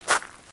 sounds / material / human / step / gravel02gr.ogg
gravel02gr.ogg